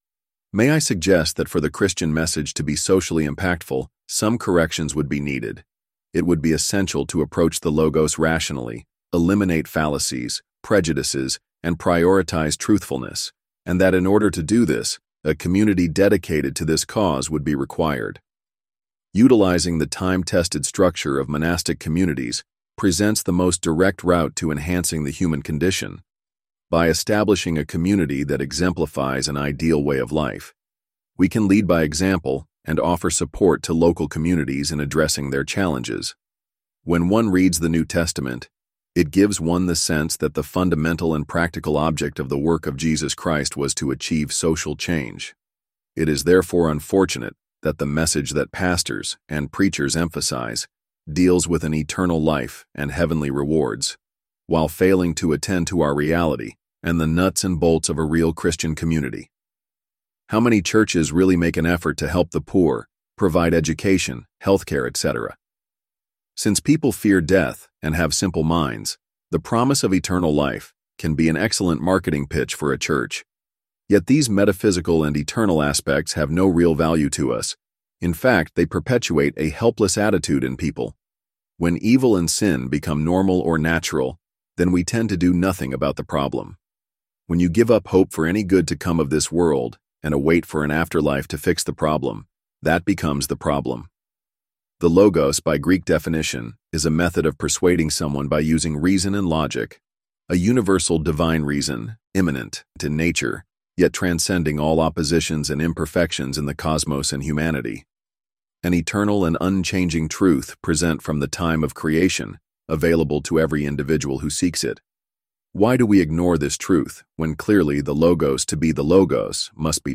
Read-Aloud